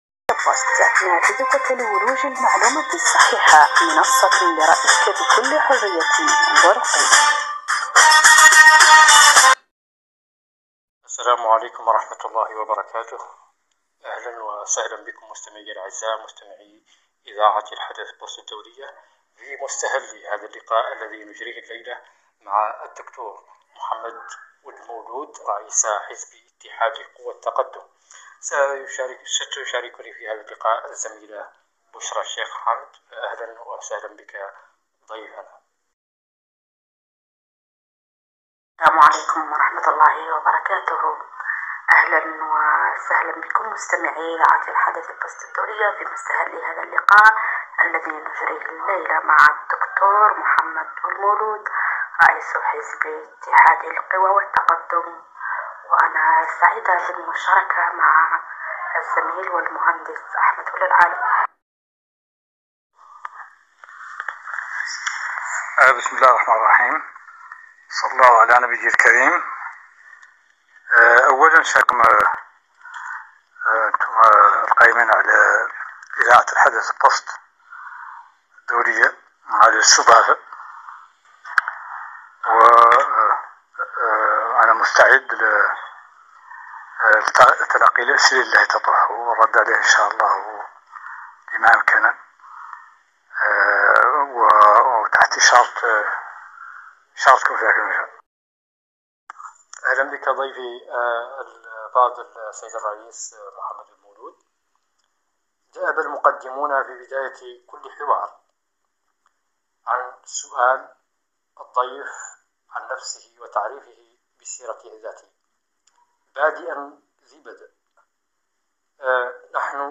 أجرت “الحدث بوست” مقابلة مع د.محمد ولد مولود رئيس حزب اتحاد قوي التقدم.